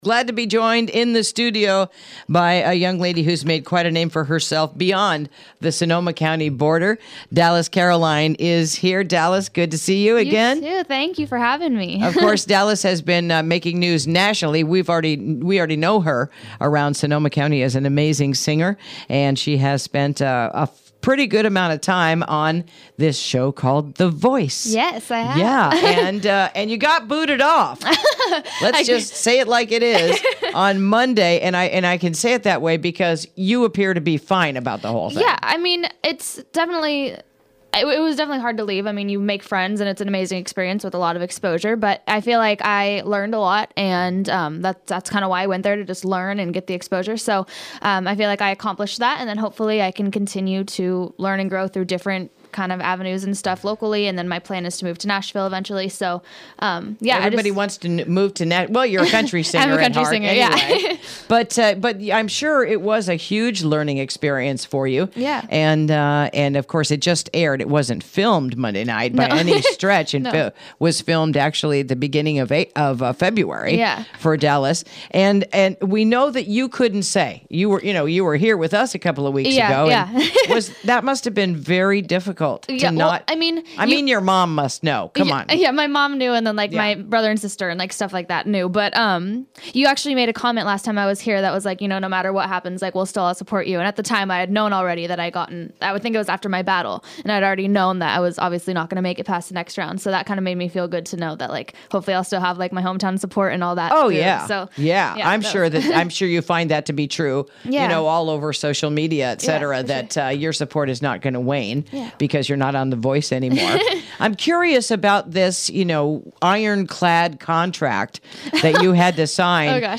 Interview: Santa Rosa Singer/Songwriter Reflects on Her Recent Experience on NBC’s “The Voice”